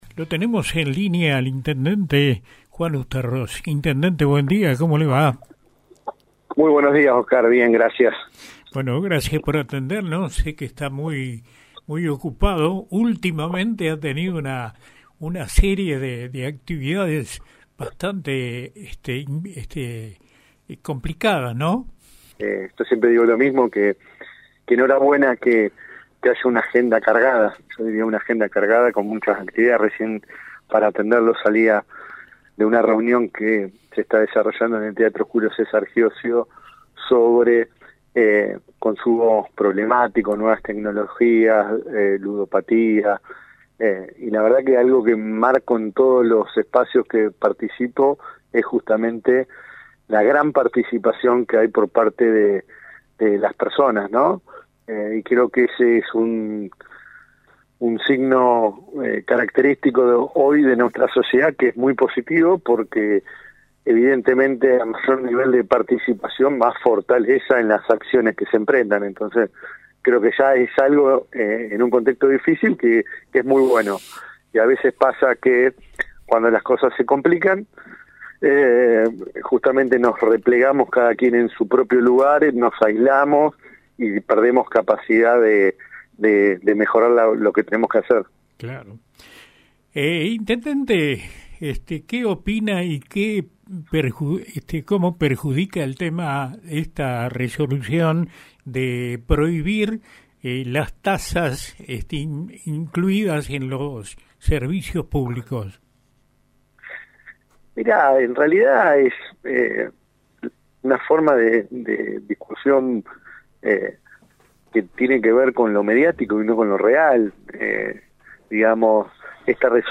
SEPTIEMBRE-12-INTENDENTE-USTARROZ.mp3